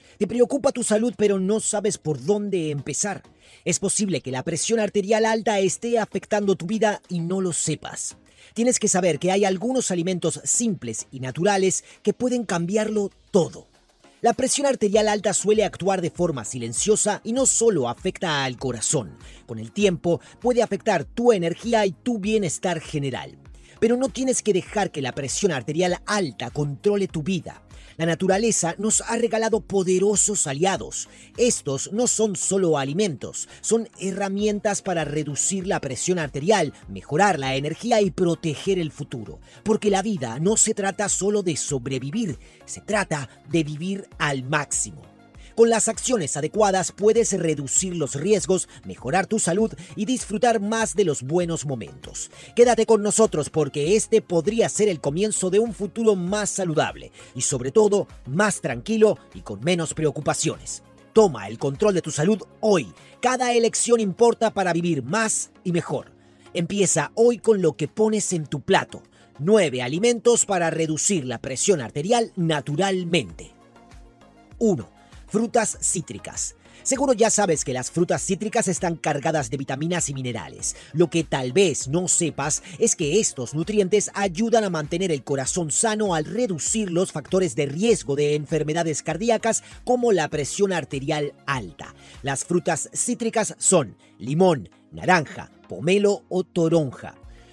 Espagnol (argentin)